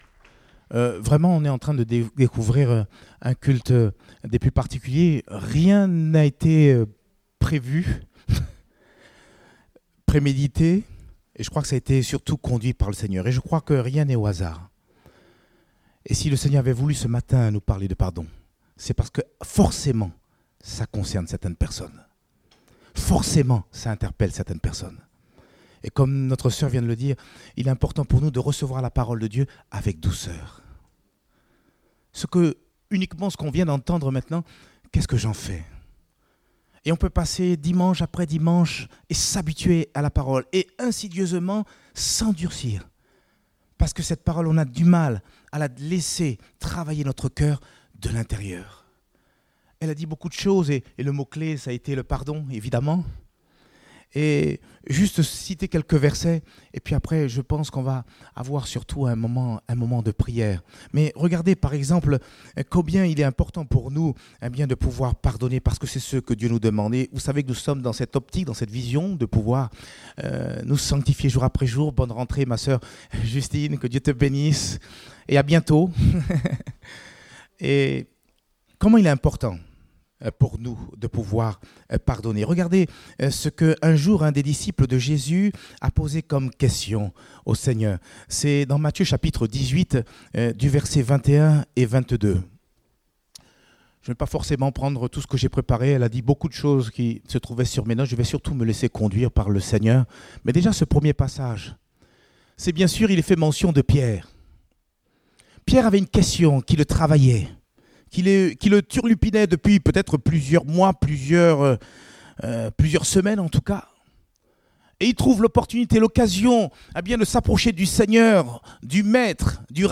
Date : 19 mai 2019 (Culte Dominical)